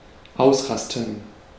Ääntäminen
IPA: [ʁə.po.ze]